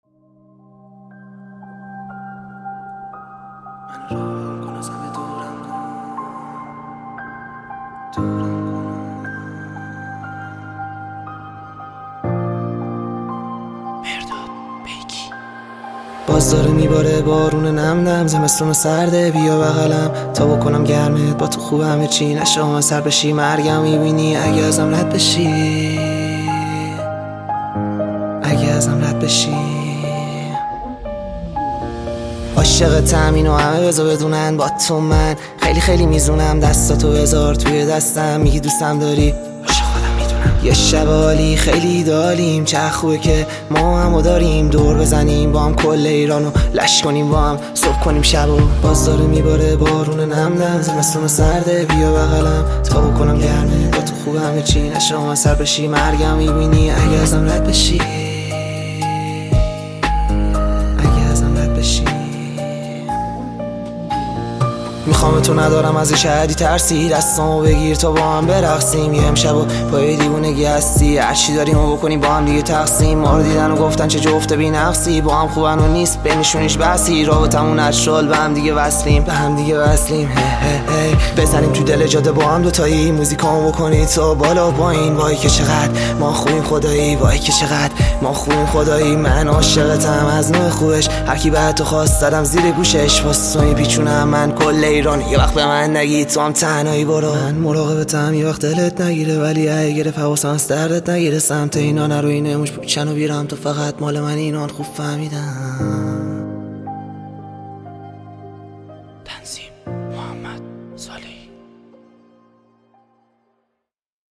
تک آهنگ ها ، رپ